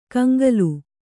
♪ kaŋgalu